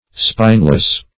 Spineless \Spine"less\, a.